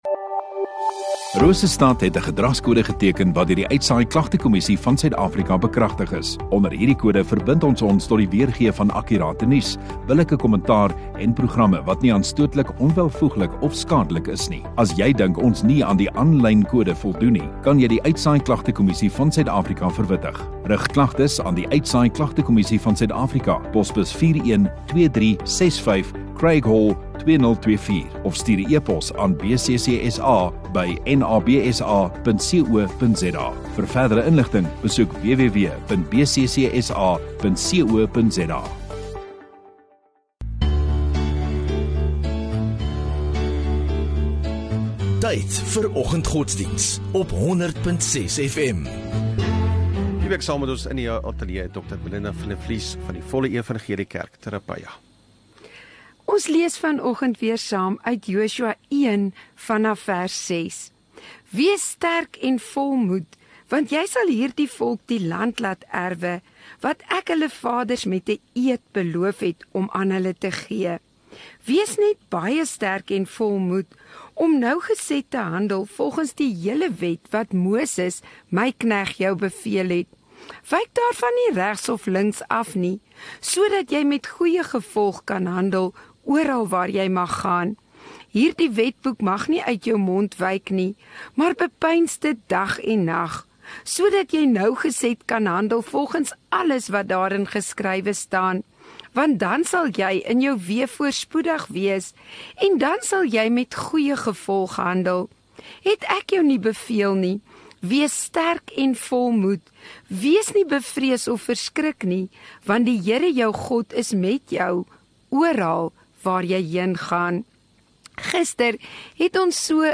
27 Nov Woensdag Oggenddiens